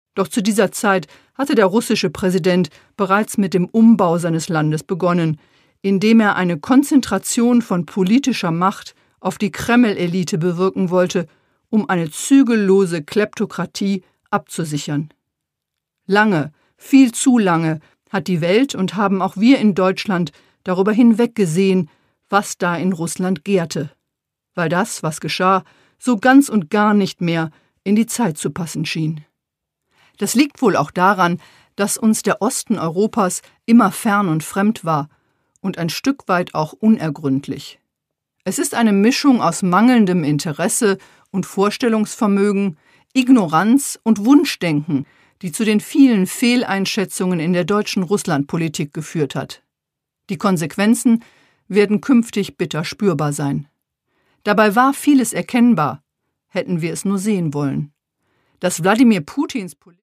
Katrin Eigendorf: Putins Krieg - Wie die Menschen in der Ukraine für unsere Freiheit kämpfen (Ungekürzte Autorinnenlesung)
Produkttyp: Hörbuch-Download
Gelesen von: Katrin Eigendorf
Das Hörbuch wird von der Autorin selbst gelesen.